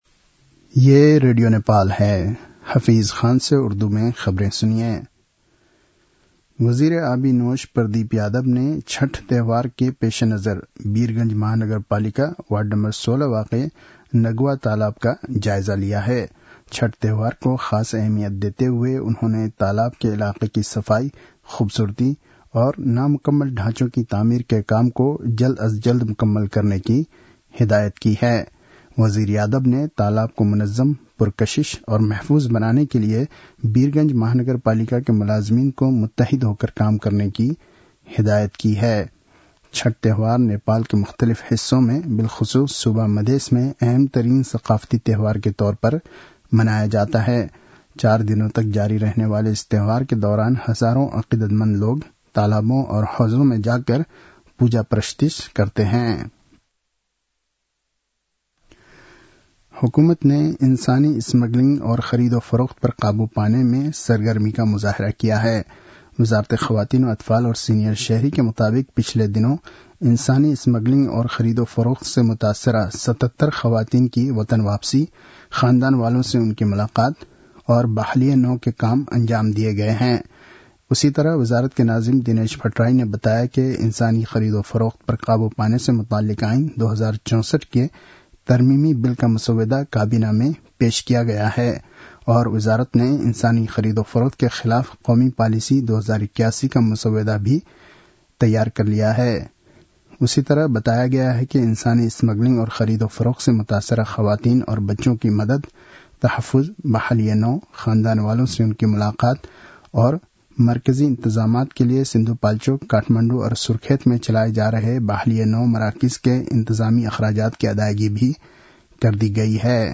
उर्दु भाषामा समाचार : २० कार्तिक , २०८१